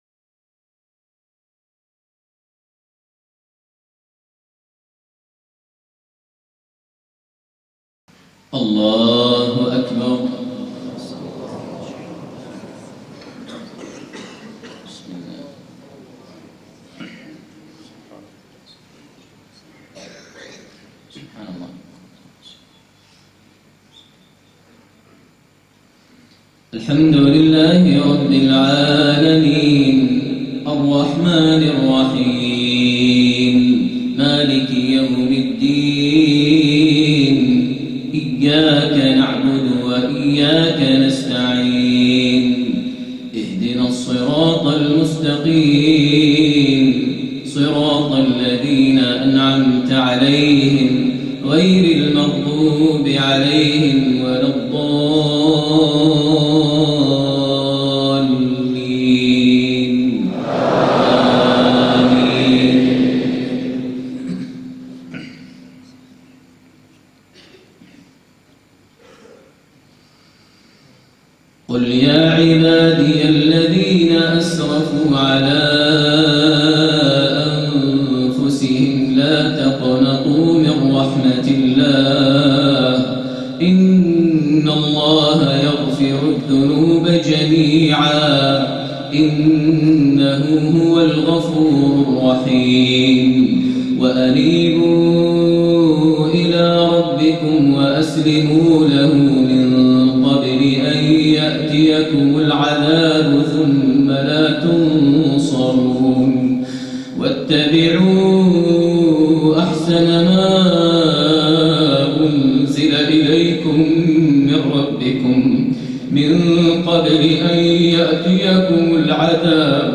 صلاة العشاء من مسجد بتلة الخرينج من سورة الزمر > زيارة الشيخ ماهر المعيقلي لدولة الكويت ١٤٣٥هـ > المزيد - تلاوات ماهر المعيقلي